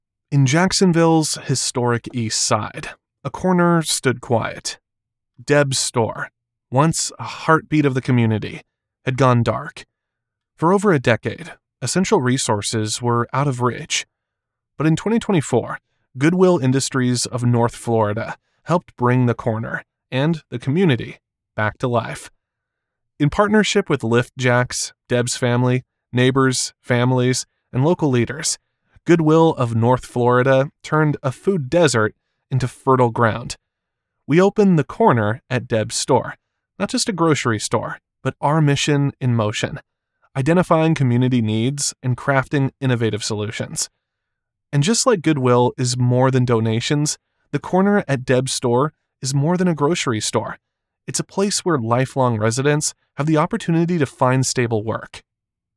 Male
Studio Quality Sample
Words that describe my voice are young voice over, american voice over, male voice over.